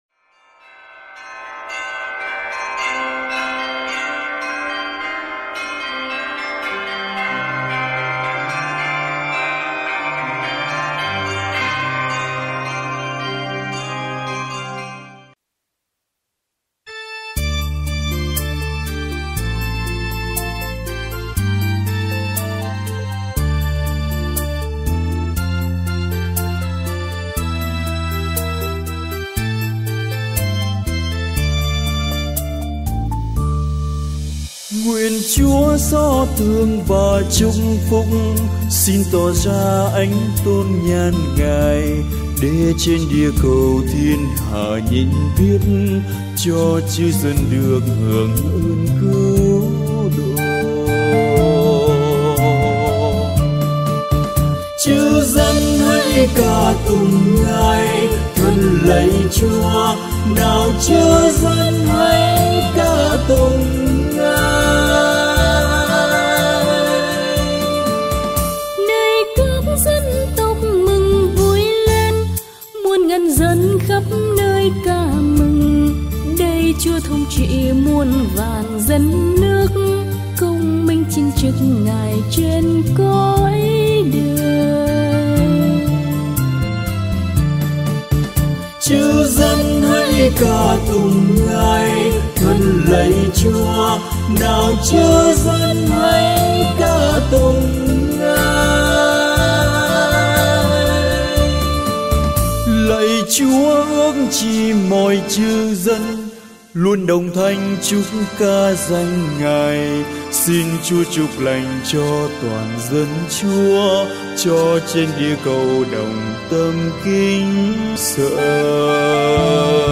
Thể loại 🌾 Nhạc Thánh Ca, 🌾 Thánh Vịnh - Đáp Ca